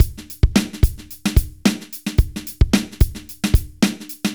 Index of /90_sSampleCDs/AKAI S6000 CD-ROM - Volume 4/Others-Loop/BPM110_Others2